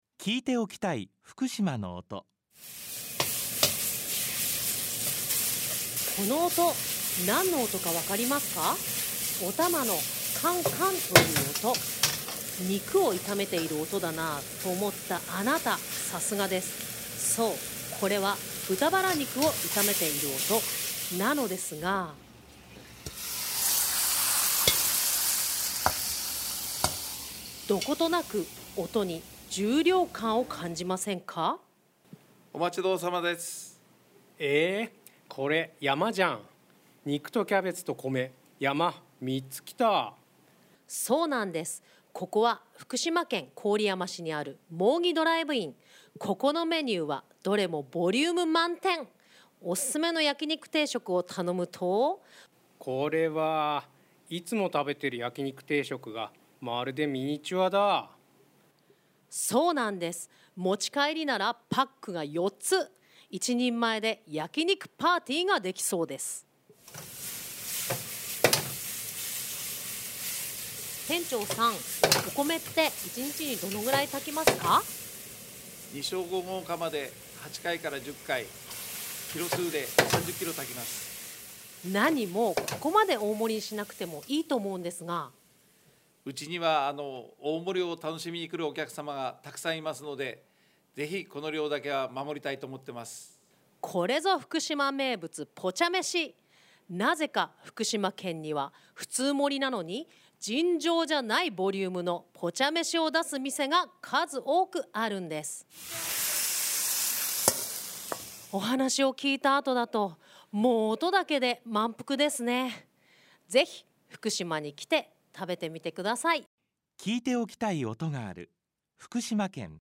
『焼肉を炒める音。』（ロケーション―郡山市）